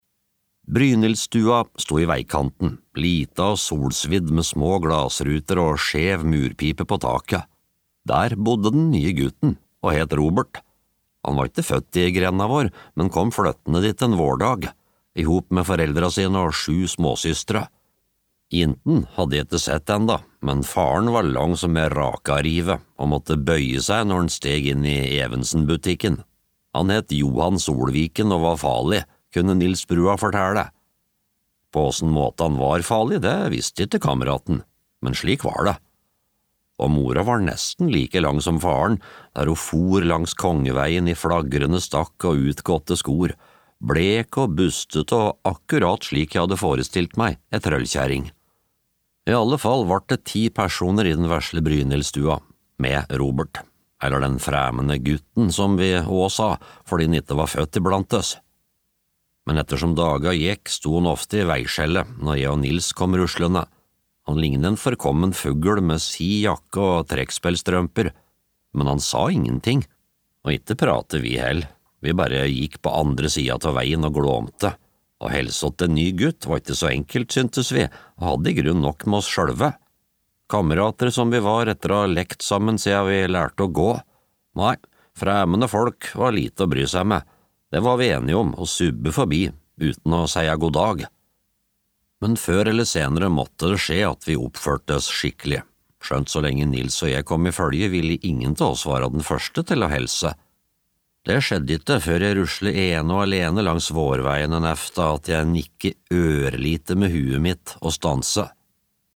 Den nye gutten (lydbok) av Vidar Sandbeck